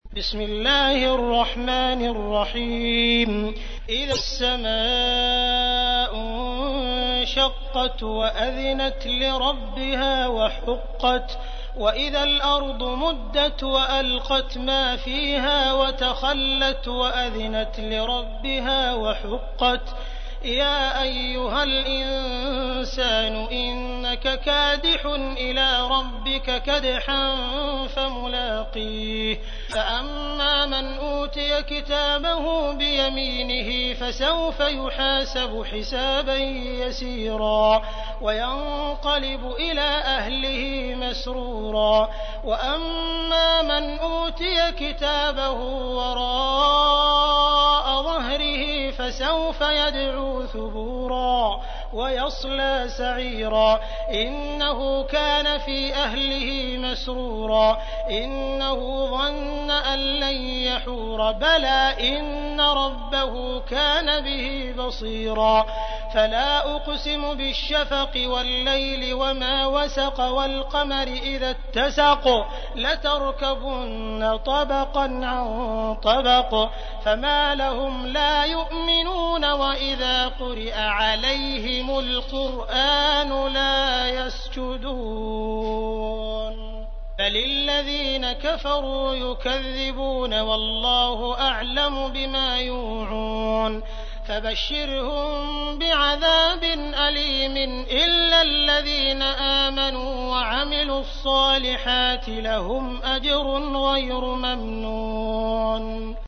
تحميل : 84. سورة الانشقاق / القارئ عبد الرحمن السديس / القرآن الكريم / موقع يا حسين